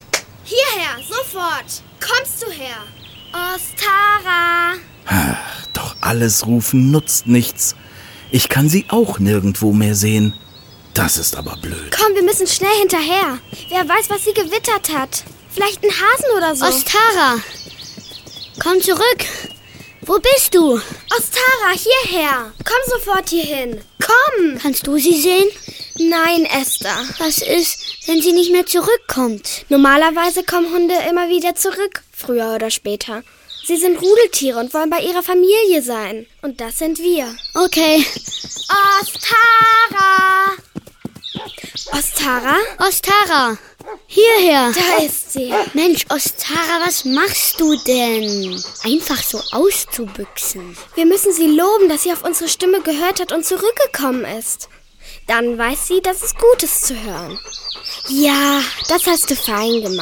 Hörspiele